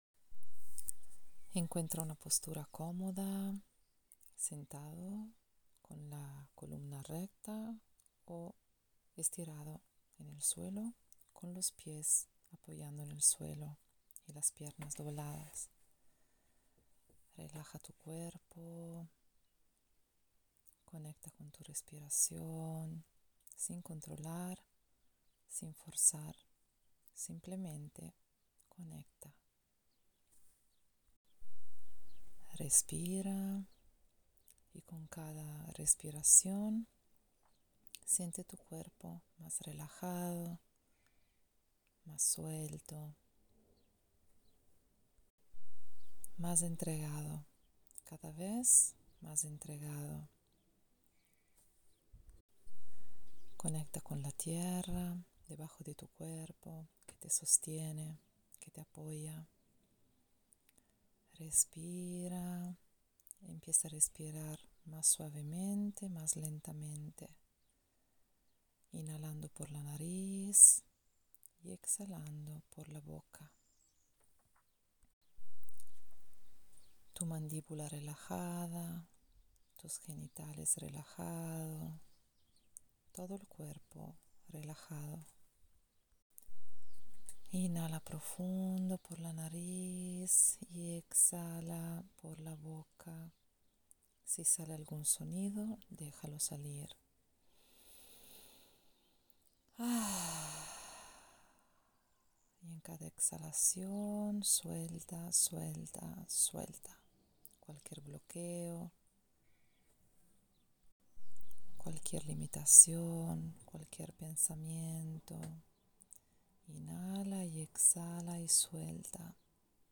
Esta visualización-relajación es para padres, hombres, y cualquiera que quiera abrir y conectar con su latido, en un pequeño viaje hacia dentro.